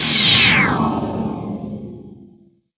8 bits Elements
Power Laser Guns Demo
Plasm_gun56.wav